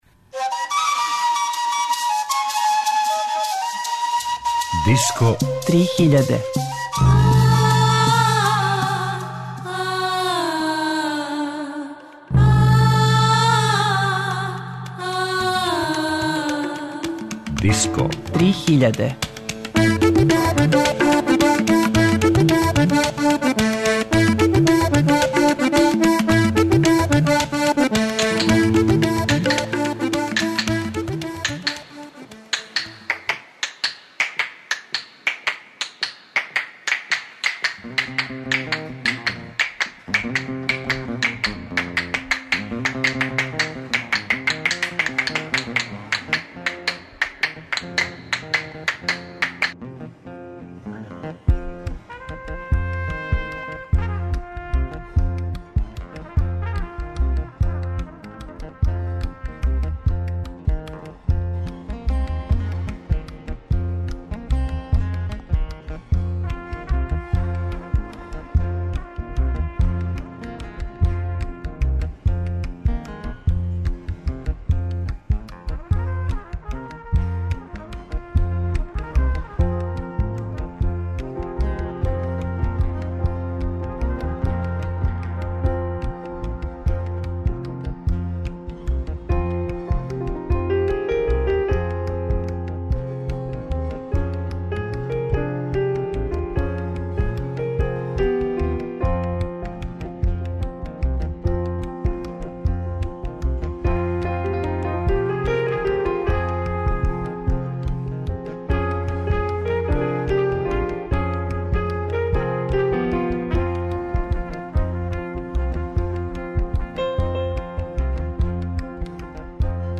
Соло албуми мајстора жичаних инструмената
свира традиционалну курдску лауту – танбур
кинеског инструмента гуженг
сам пева и свира жичани инструмент свог народа – симби
world music